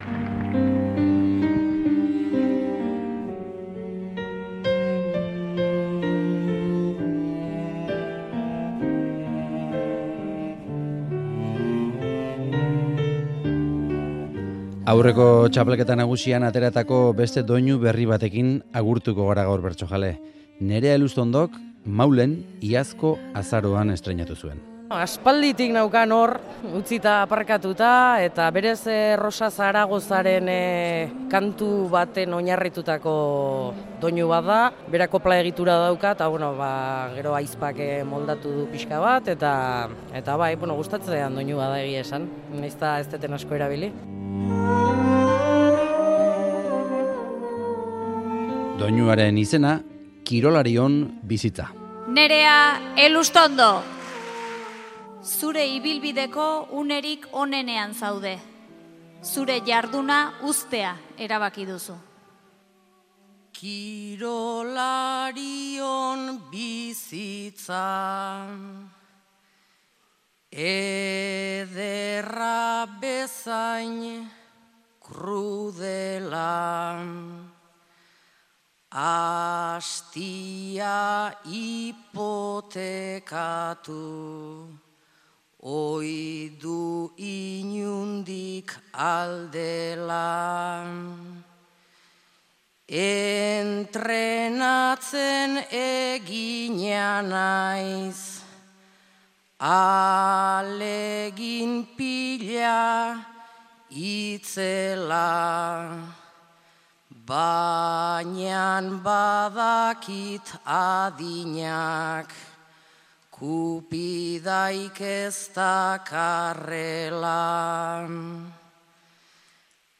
Txapelketa Nagusian plazaratuzuen doinu berria